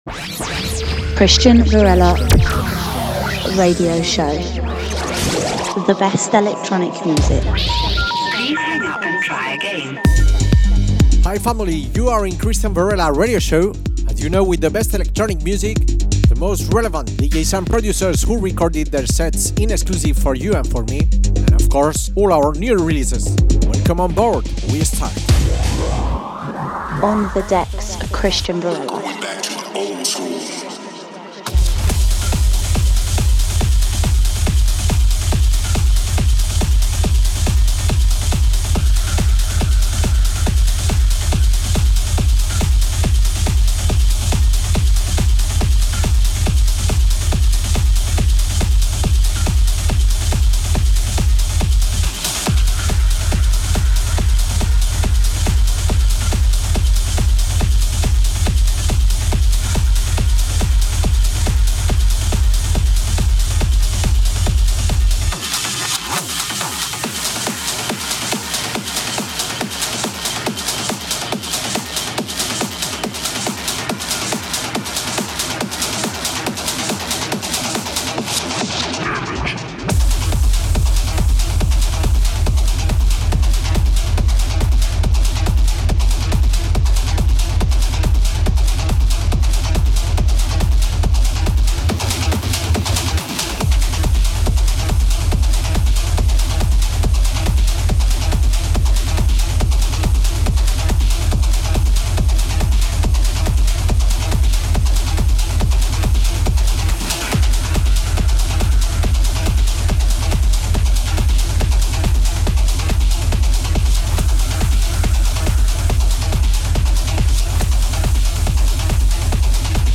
Radio Show Specialised in Techno Music